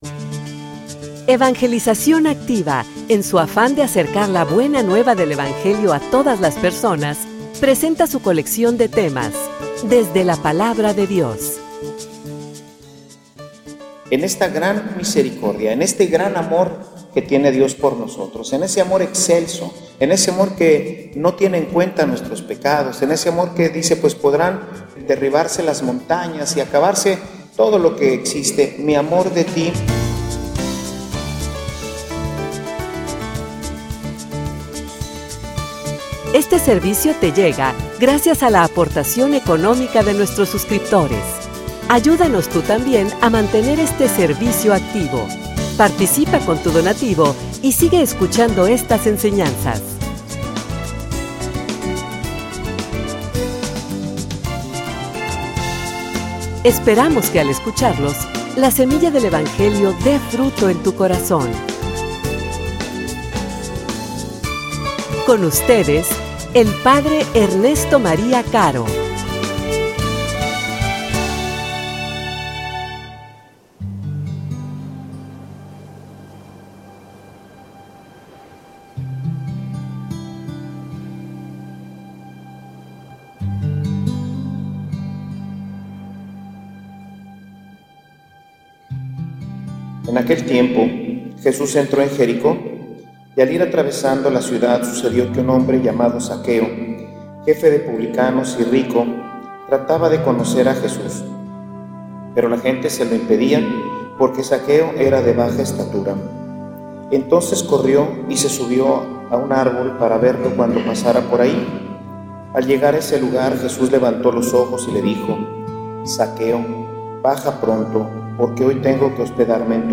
homilia_La_respuesta_es_importante.mp3